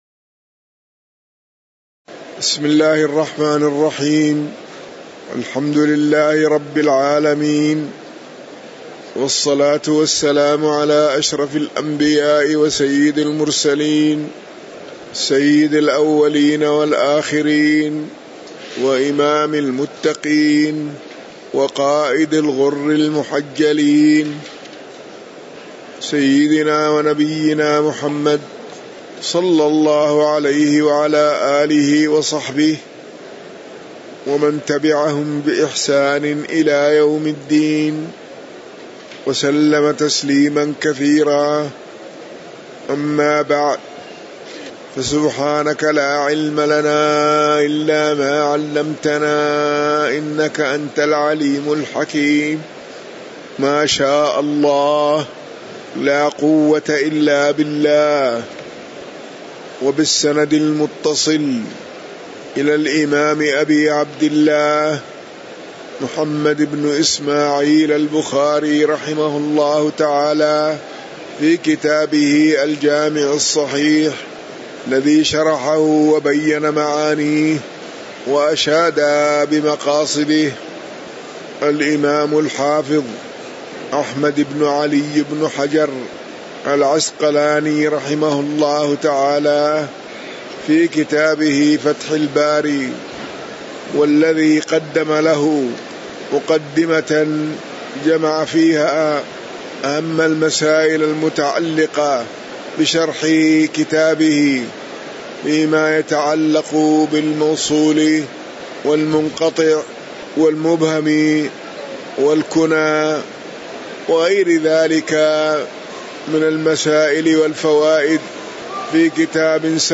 تاريخ النشر ١٩ صفر ١٤٤٠ هـ المكان: المسجد النبوي الشيخ